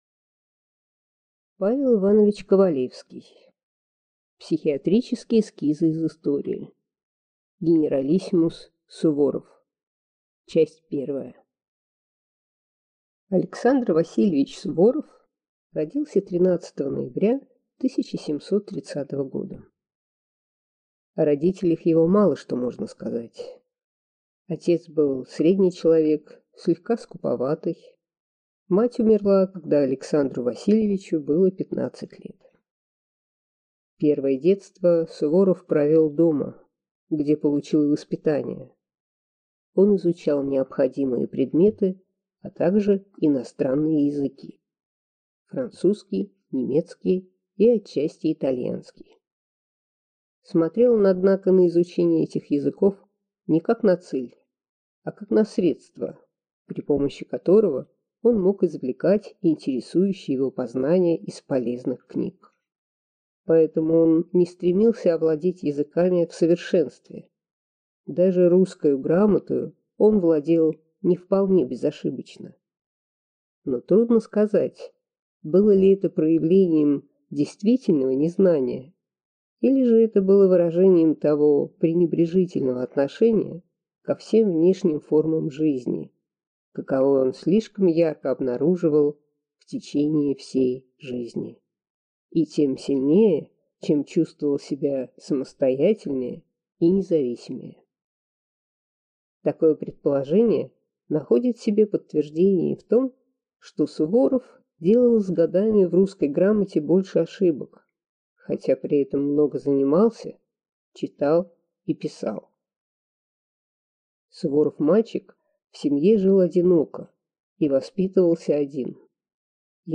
Аудиокнига Генералиссимус Суворов | Библиотека аудиокниг